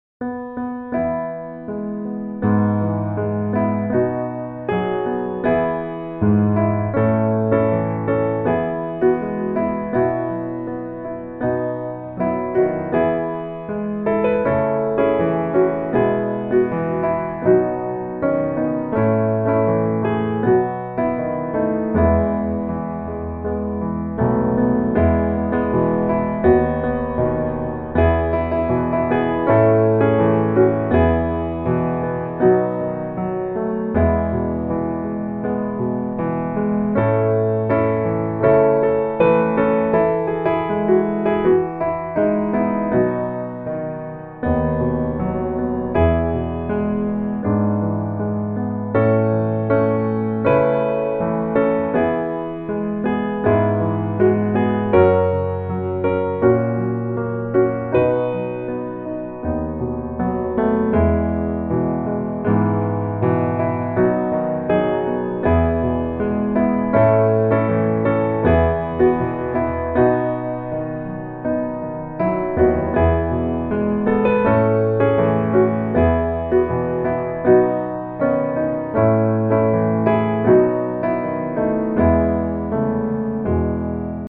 E大調